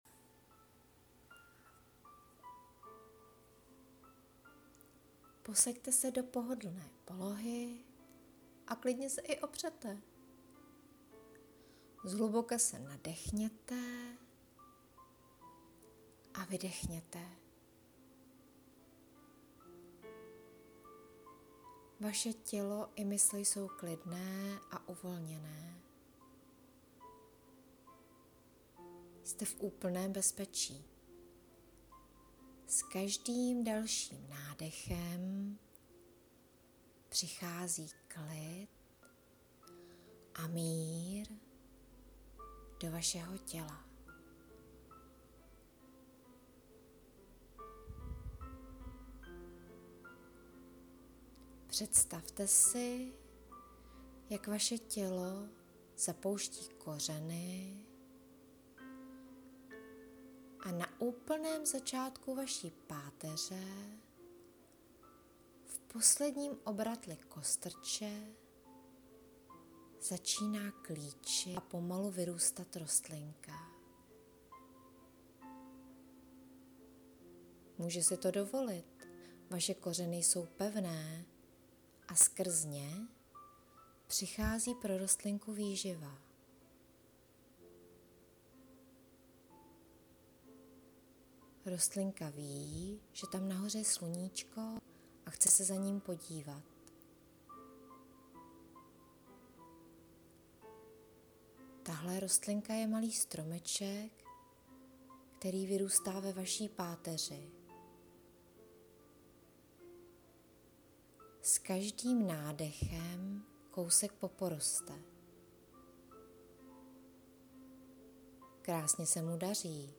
meditace pro rovná záda
Tak se totiž vedeným meditacím říká.